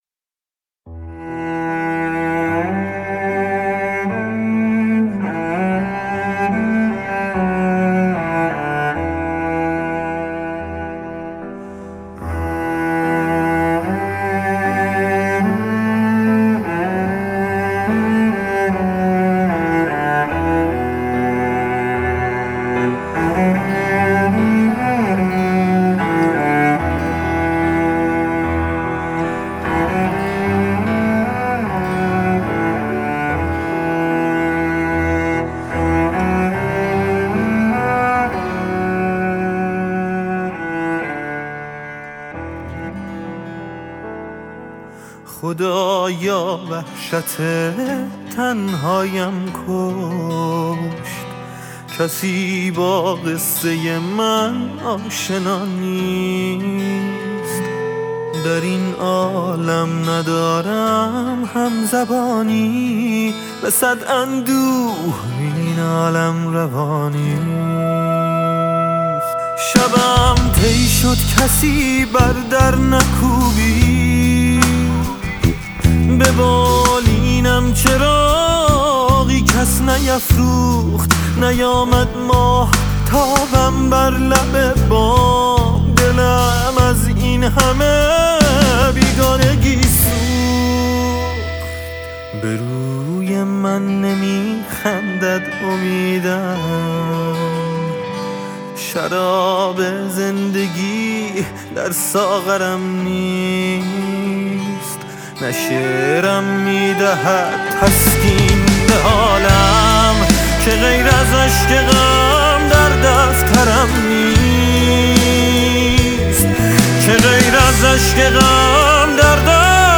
آهنگهای پاپ فارسی
موزیک بی کلام